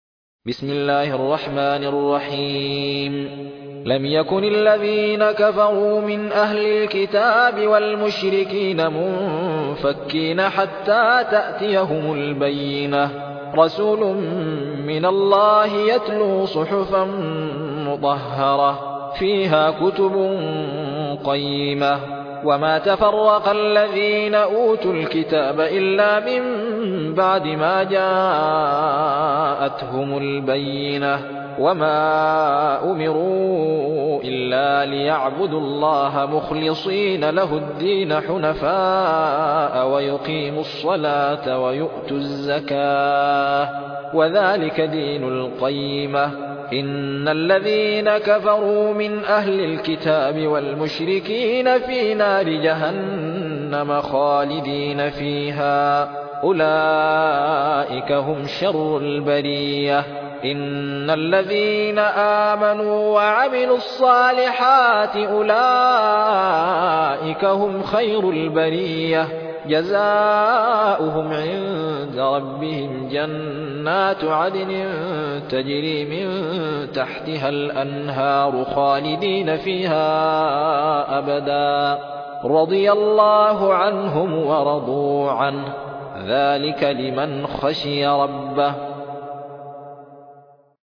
المصحف المرتل - حفص عن عاصم